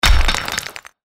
n1_ui_sound_mine_start.mp3